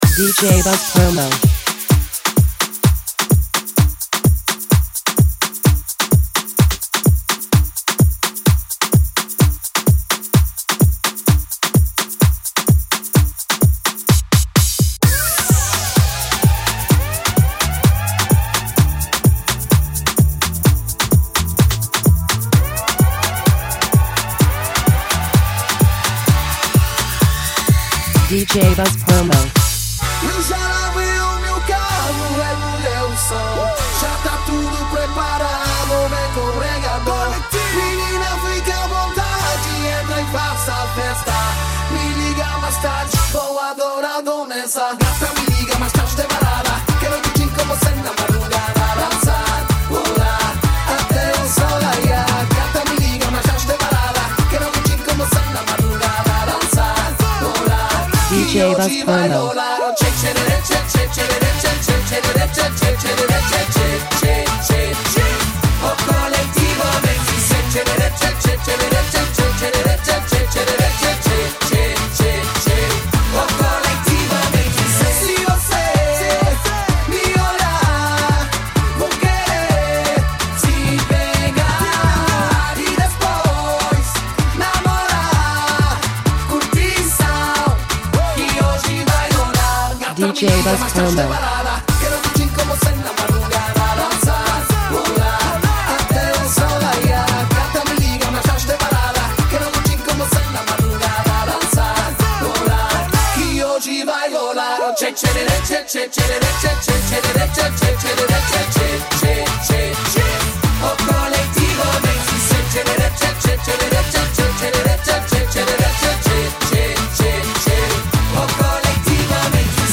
DJs Remixes!